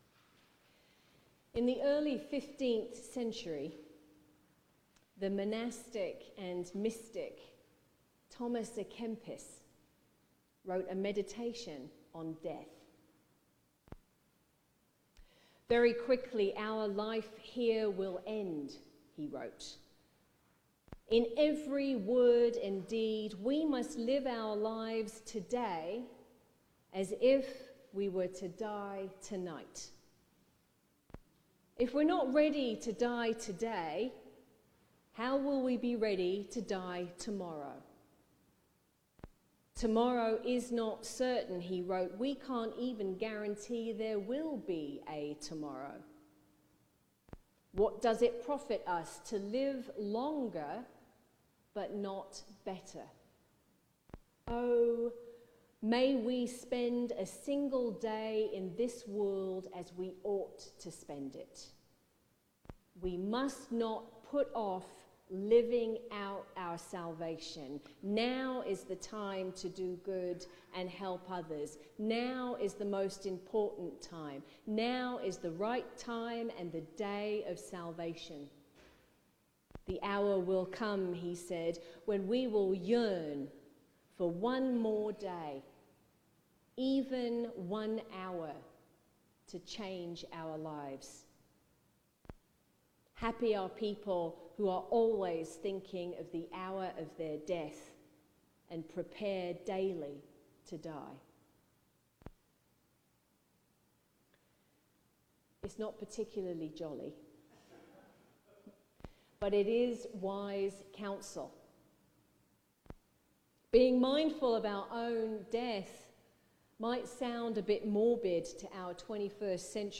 Ash Wednesday – 2/18/26 – Sermon for Ash Wednesday: memento mori